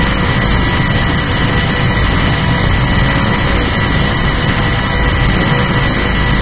turbofanOperate.ogg